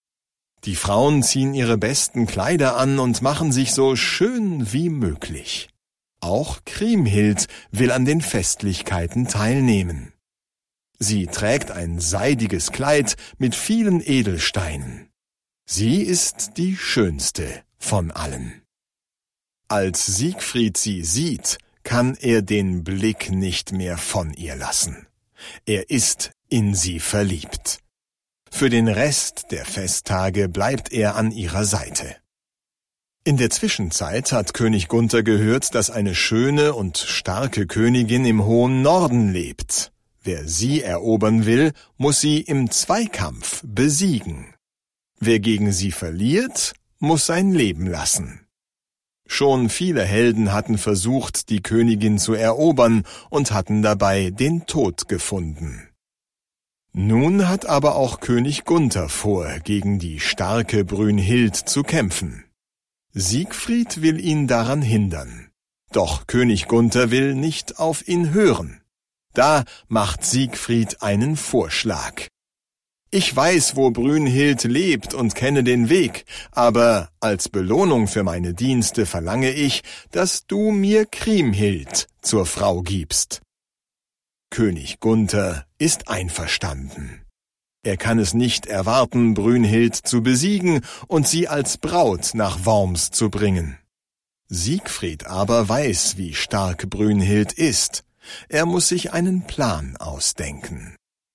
Das Nibelungenlied (DE) audiokniha
Ukázka z knihy